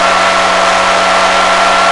gearwhine.wav